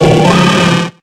Cries